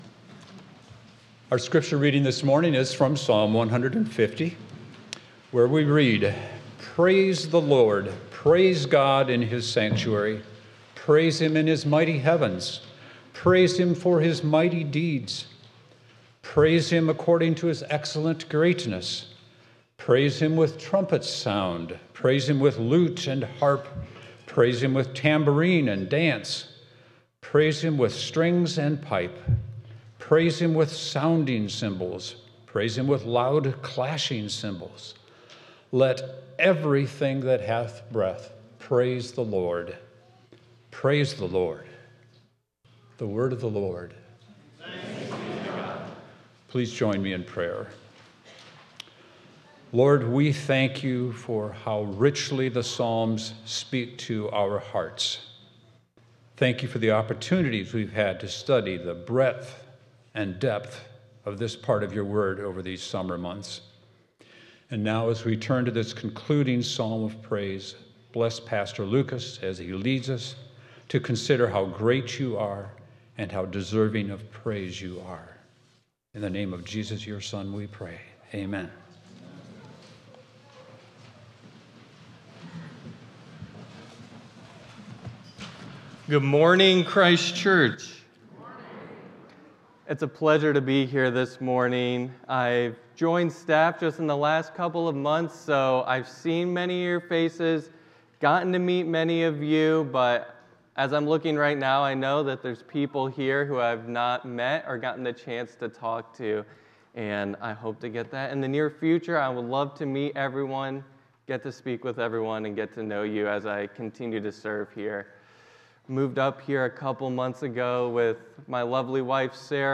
8.31.25 Sermon.m4a